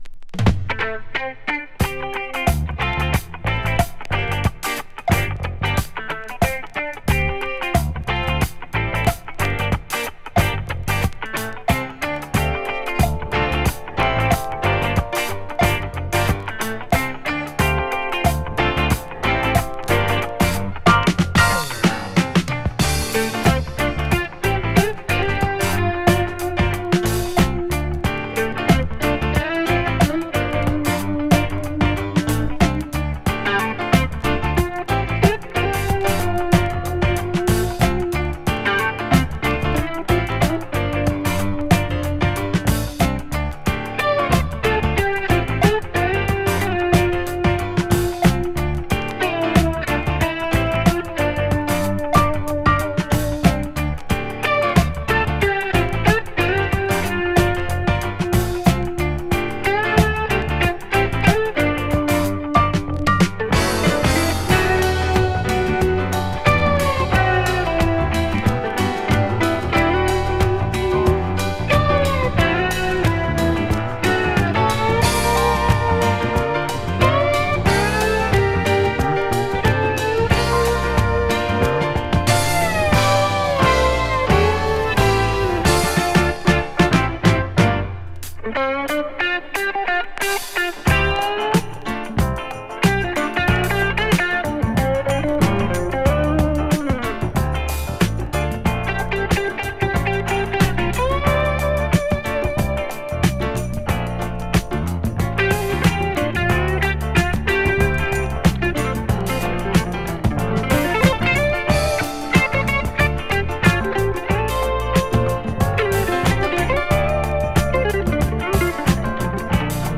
歌うギターを堪能できる人気曲